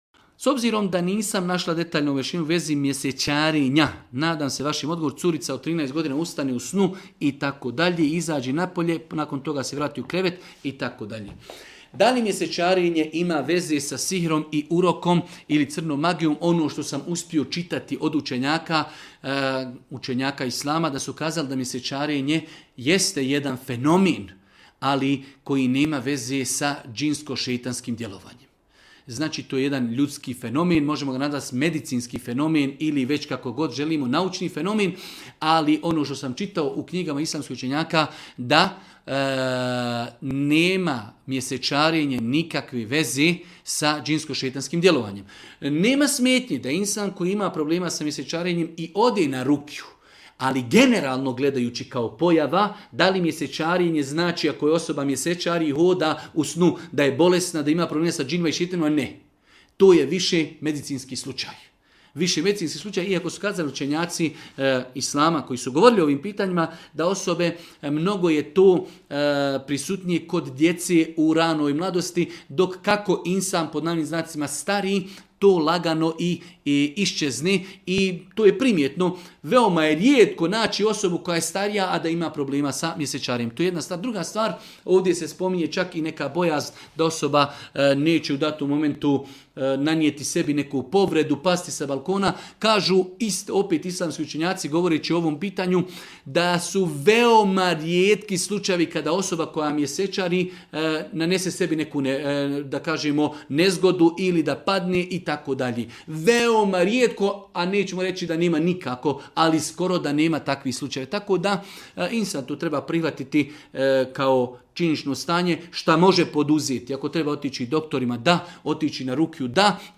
u video predavanju